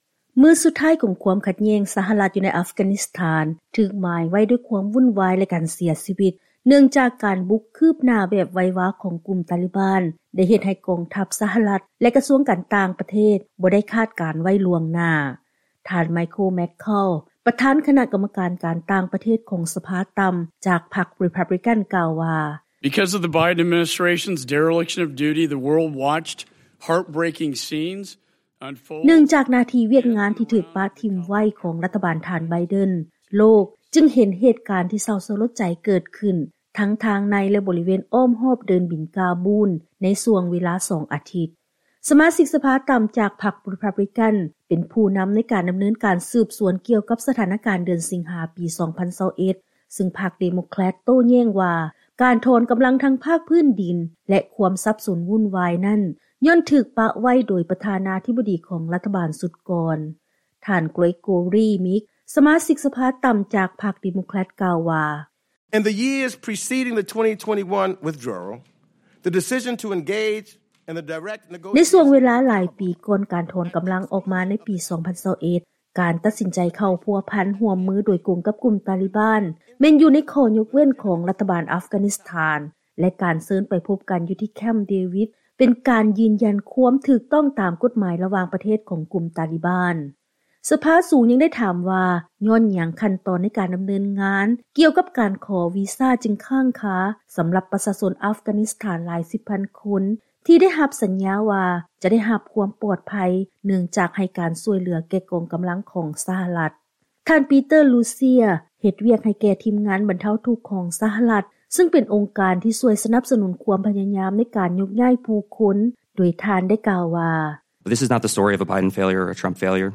ເຊີນຟັງລາຍງານກ່ຽວກັບ ສະມາຊິກສະພາສະຫະລັດ ດໍາເນີນການສືບສວນກ່ຽວກັບຄວາມລົ້ມແຫຼວ ໃນການຖອນໂຕອອກຈາກ ອັຟການິສຖານ